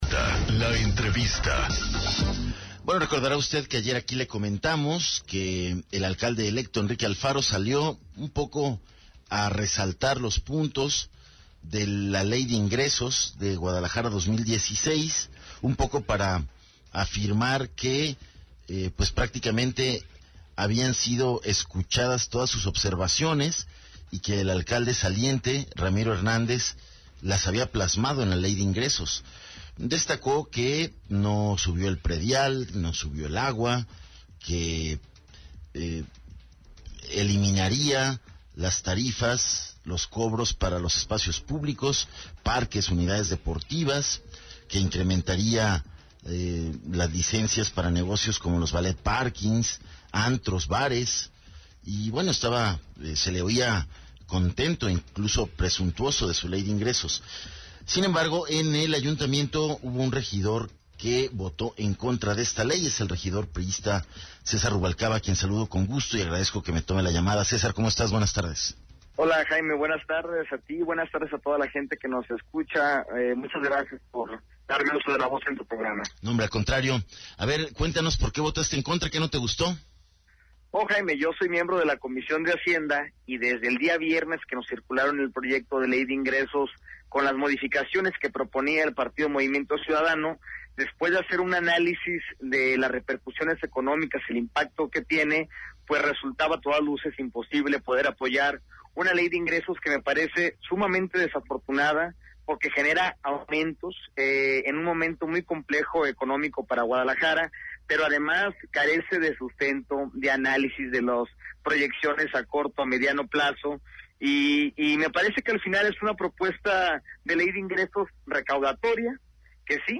ENTREVISTA 270815